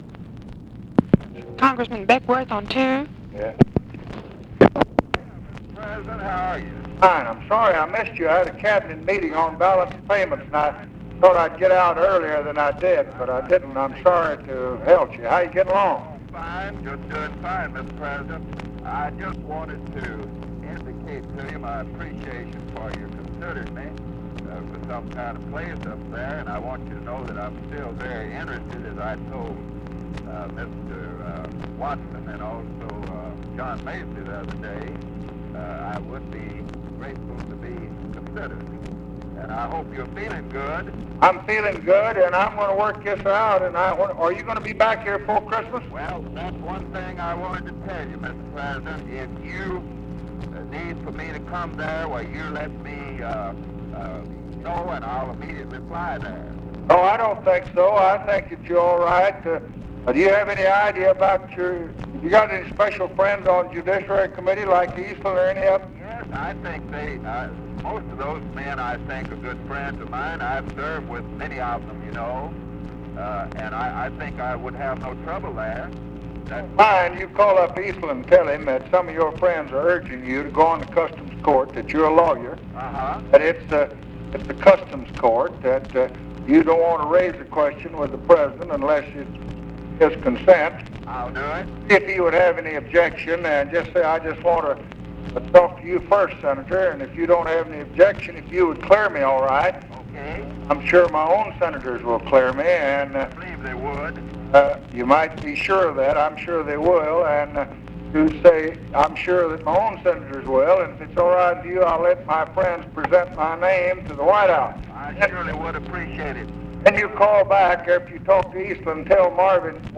Conversation with LINDLEY BECKWORTH, December 10, 1966
Secret White House Tapes